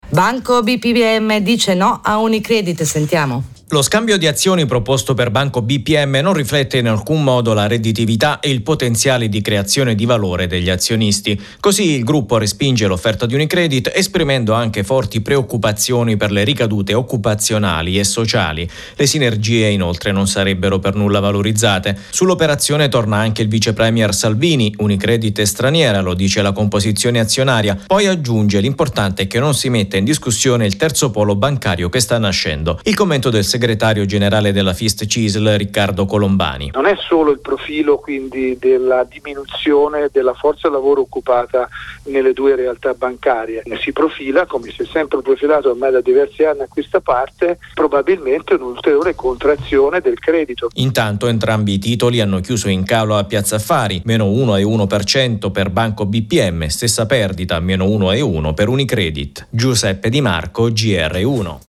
interviene nuovamente sull’operazione Unicredit-Banco Bpm ai microfoni di Rai Gr1